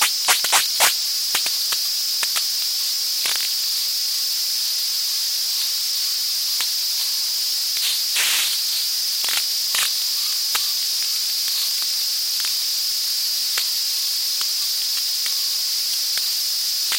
Base frequency modulation by subvocal murmur.
ultrasound base frequency modulation subvocal murmur through speaker
Clearer with in-ear microphones and adjust volume as required to hear subvocal modulation.
ultrasound-pro-modulation-subvocal-jack-and-jill.mp3